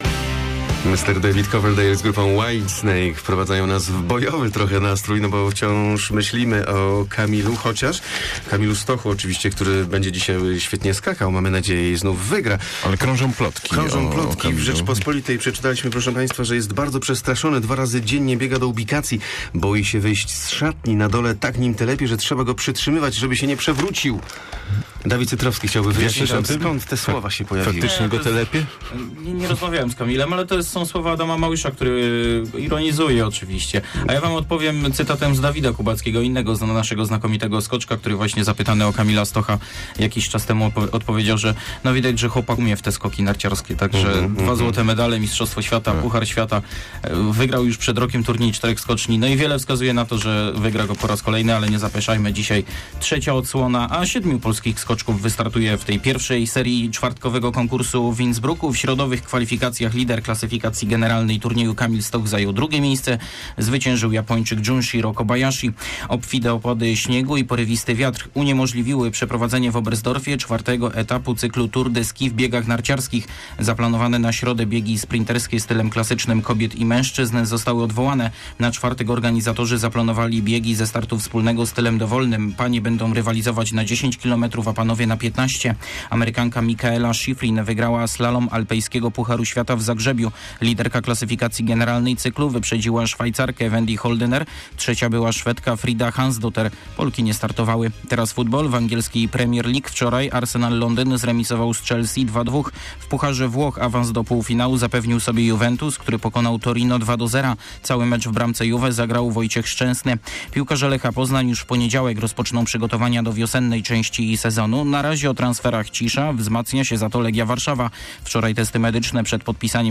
04.01 serwis sportowy godz. 7:45